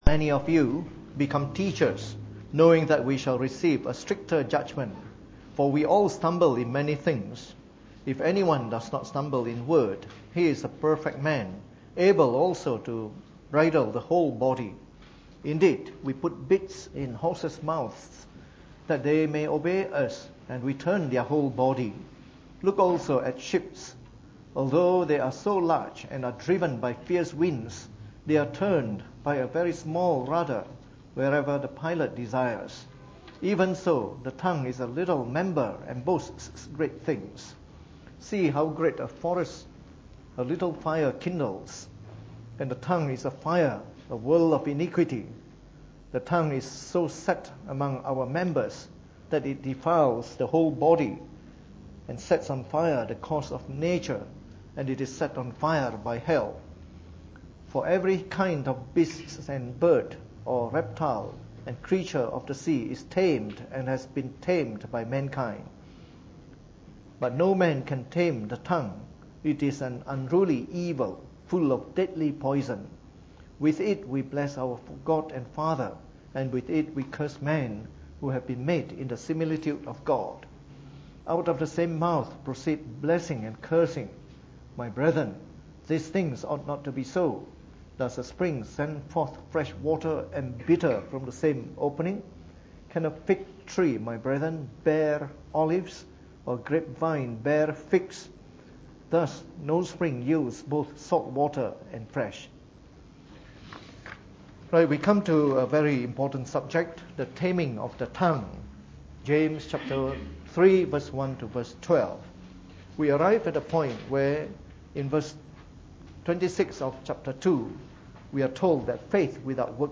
Preached on the 4th of November 2015 during the Bible Study, from our series on the Epistle of James.